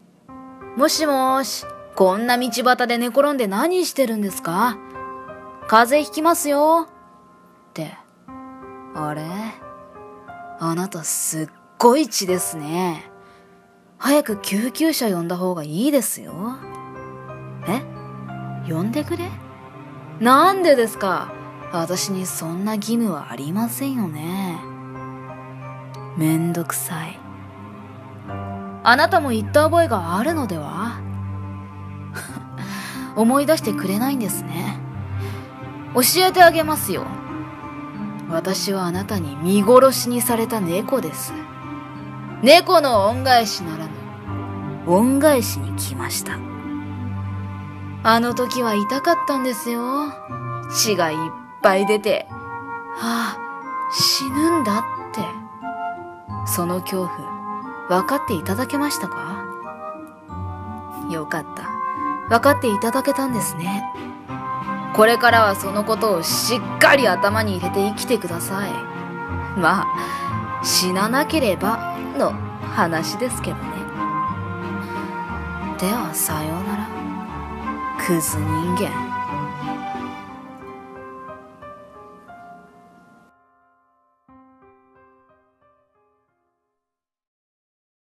▼一人声劇台本▼